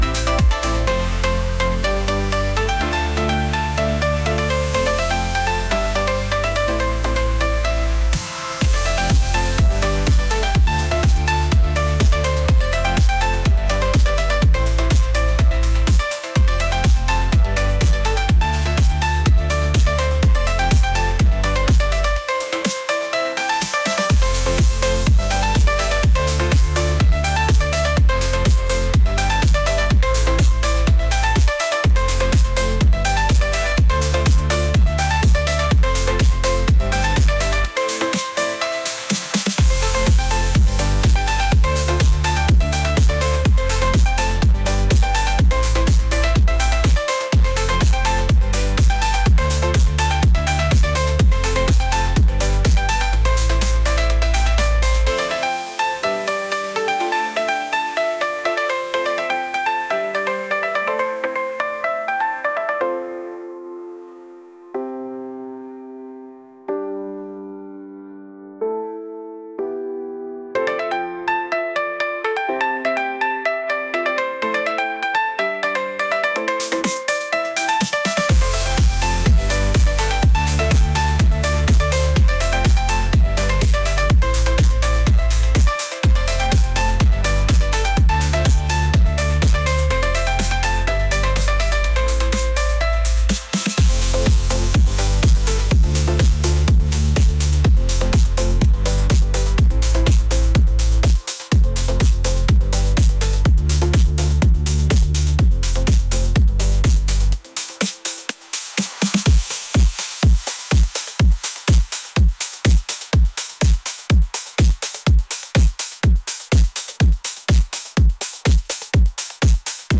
upbeat | electronic